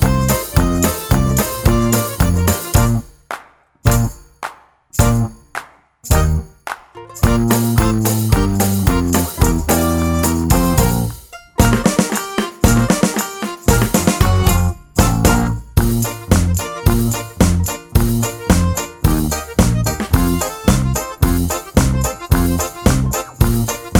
Comedy/Novelty